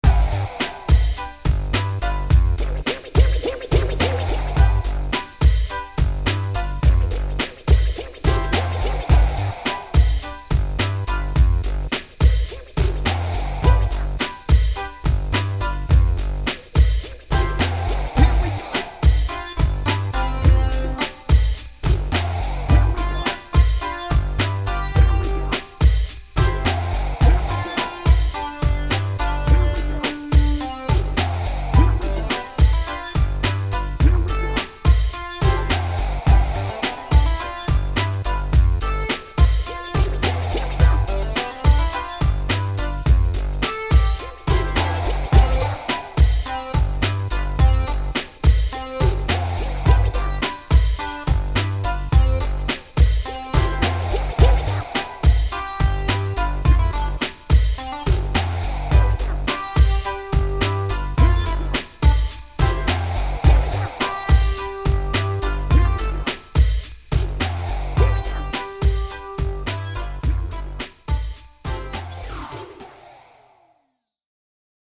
ポップで楽しい音楽
軽快でさわやかな感じを演出しています。